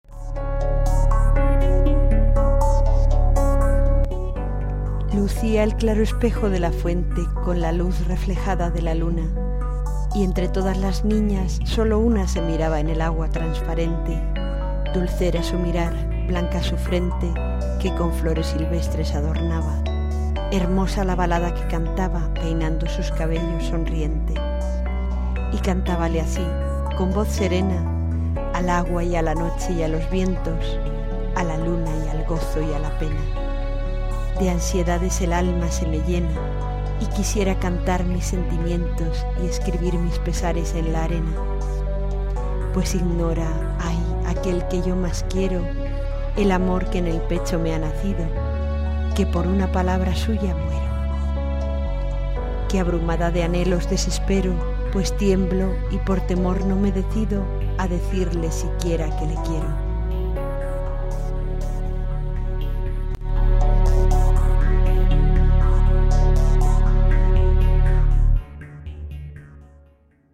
Inicio Multimedia Audiopoemas El espejo de la fuente.